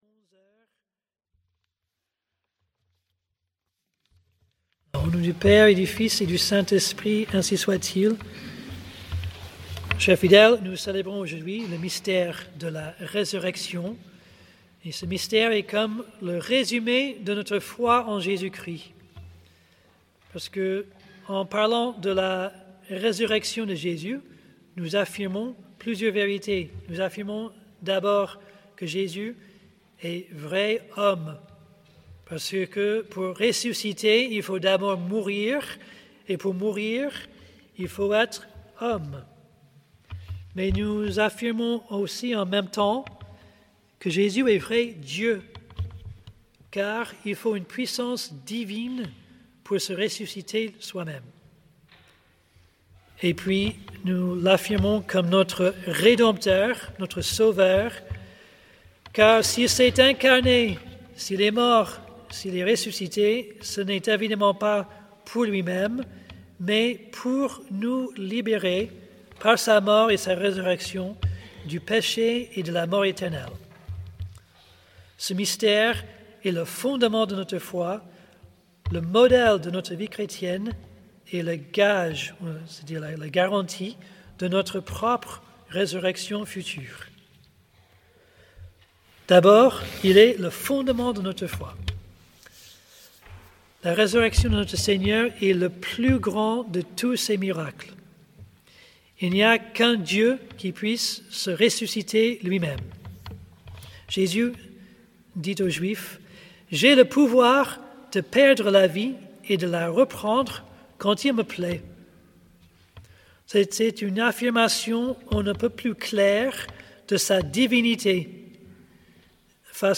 Type: Sermons Occasion: Fête de Pâques